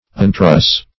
Untruss \Un*truss"\, v. t. [1st pref. un- + truss.]